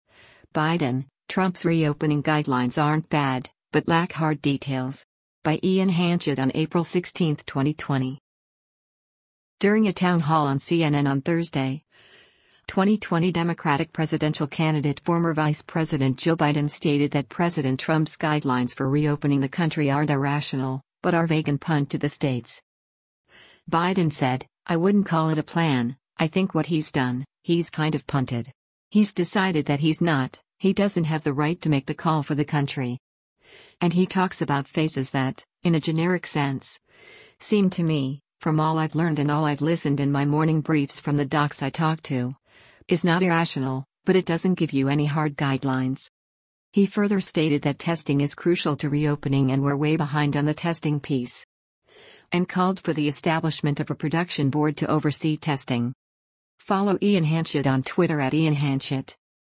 During a town hall on CNN on Thursday, 2020 Democratic presidential candidate former Vice President Joe Biden stated that President Trump’s guidelines for reopening the country aren’t “irrational,” but are vague and punt to the states.